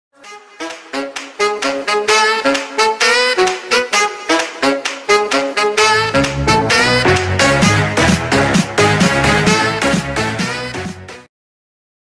free-twitch-alert-followersubdonation-sound-effect-8.mp3